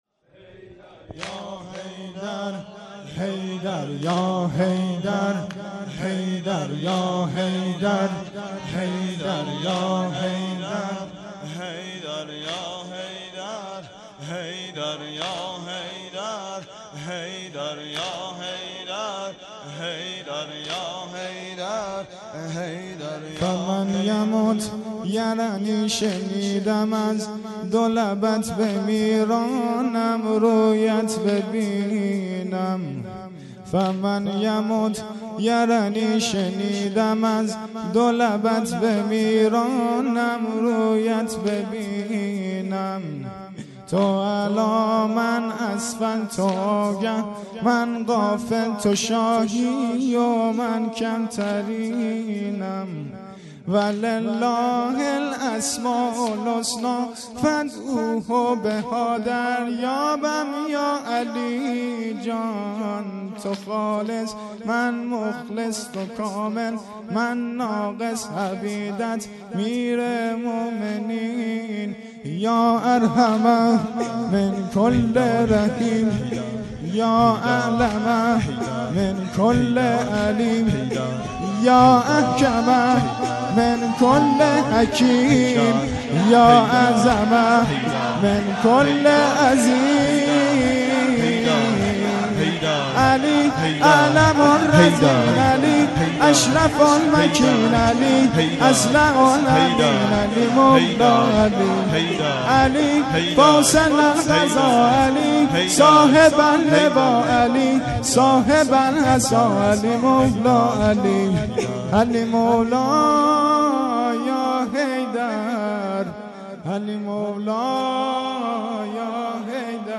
شب های قدر 1401